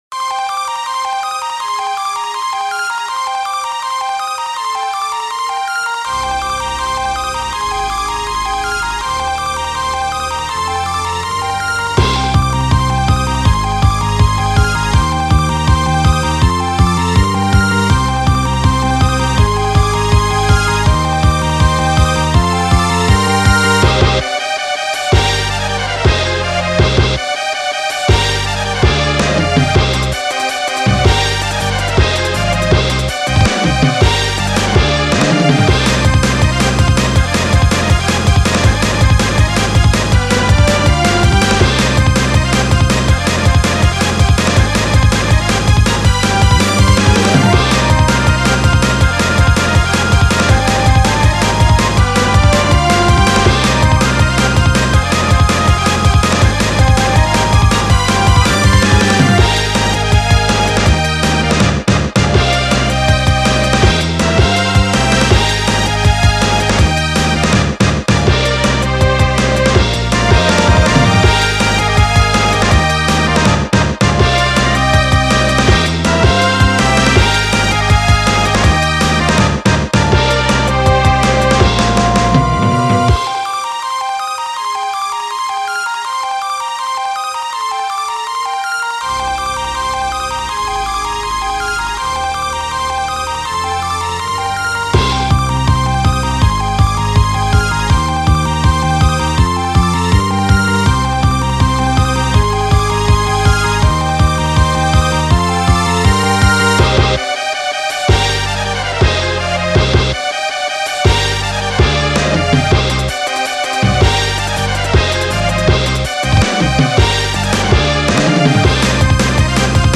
ジャンルスーパーファミコン風(ゴシック、ロック)
BPM１６２
使用楽器16-Bit音源(ギター、ヴァイオリン、ピアノ、チェレスタ)
ストリングスの音が当時のノスタルジック感を際立たせております。